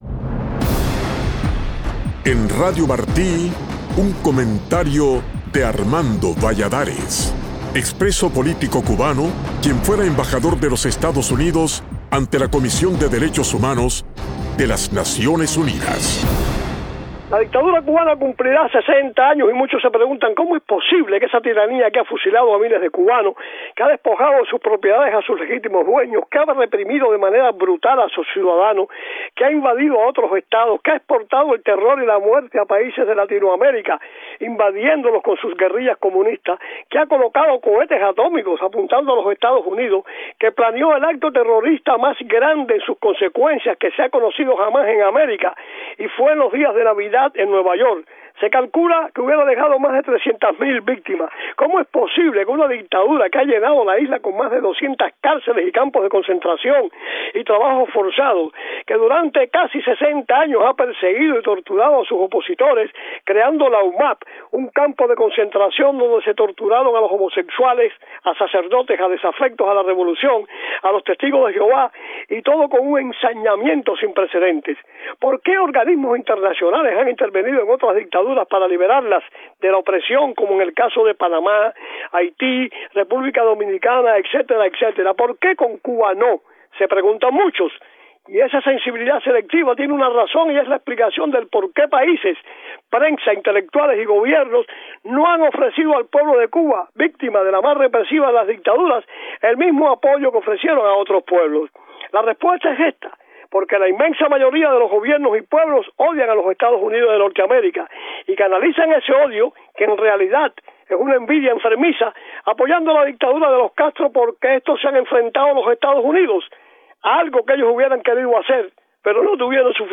El exembajador de EEUU ante ONU Armando Valladares responde a esa interrogante en este comentario.